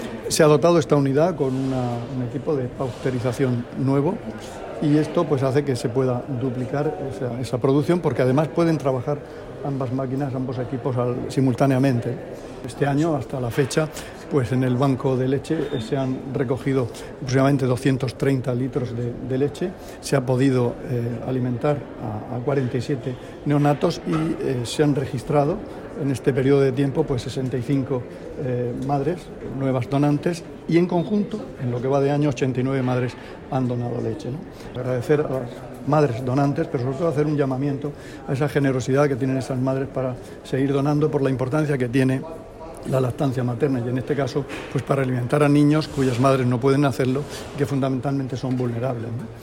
Declaraciones del consejero de Salud sobre las novedades del Banco regional de Leche Materna.